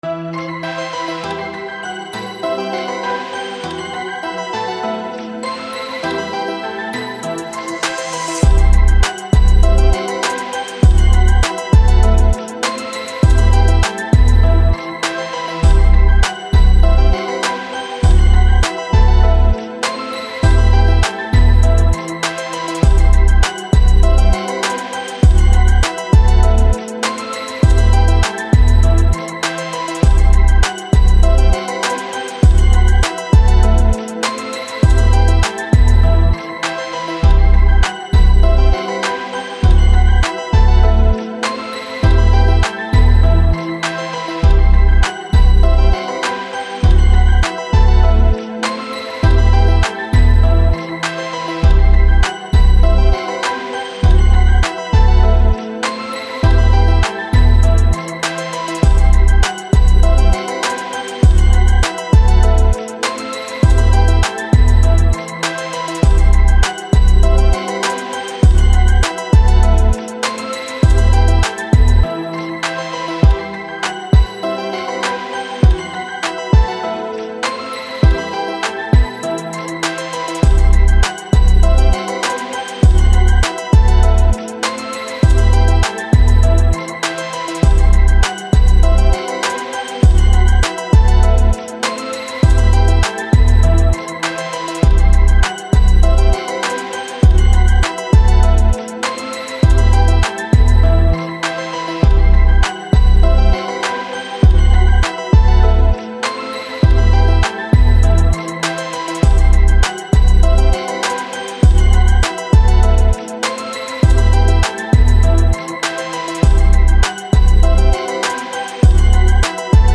Жанр: hip-hop, rep, phonk, WITCH HOUSE